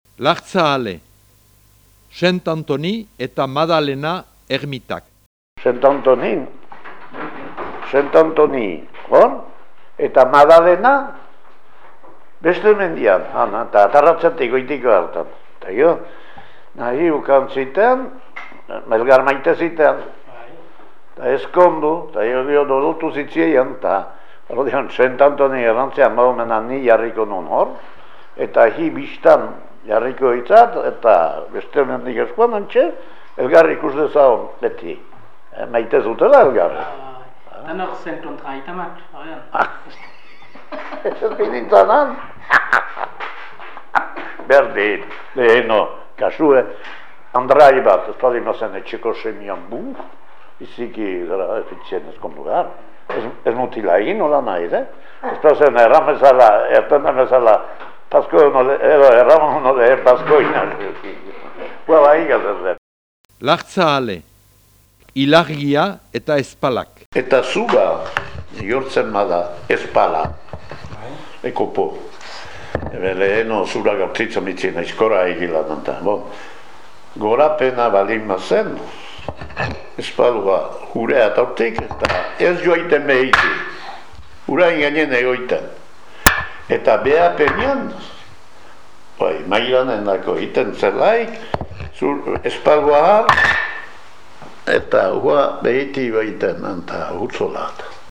6.17. LARZABALE-ARROZE-ZIBITZE
Ilargiak zur kontuetan duen eragina azaltzen du maiasturu edo zurgin den Larzabaleko lekukoak.